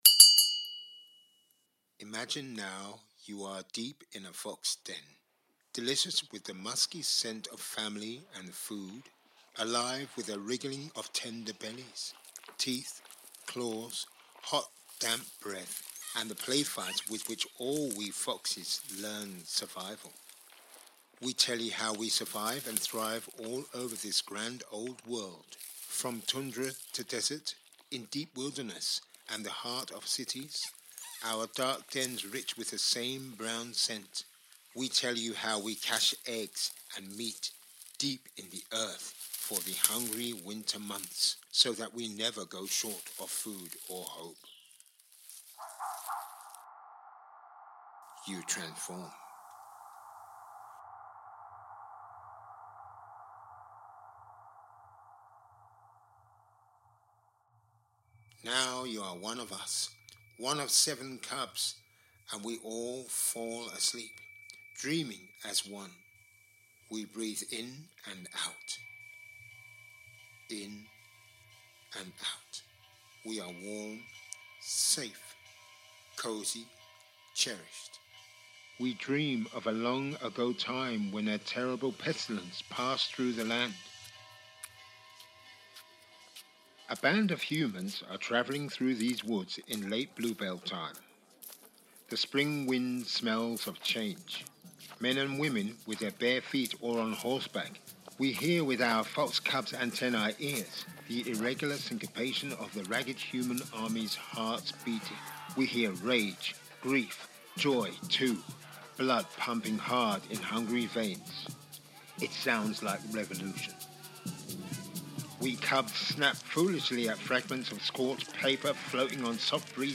Lore of the Wild – an audio storytelling walk through the woods